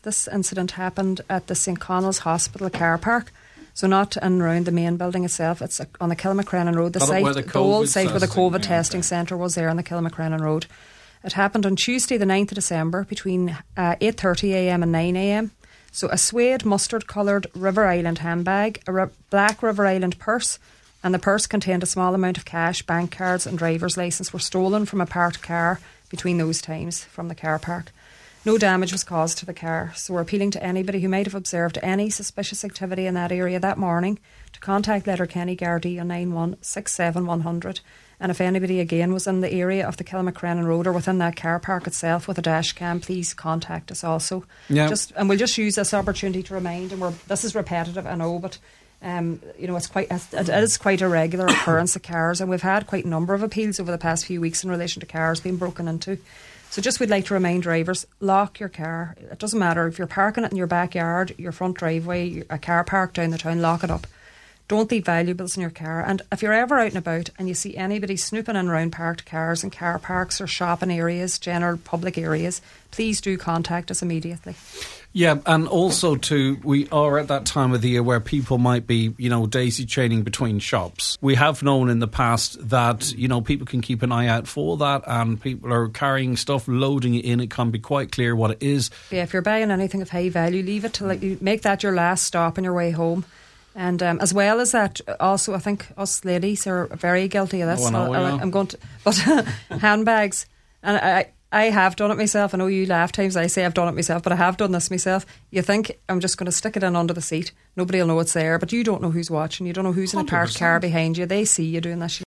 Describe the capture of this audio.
on this morning’s Nine ’til Noon Show urged the public to ensure their vehicles are locked, particularly while doing their Christmas shopping: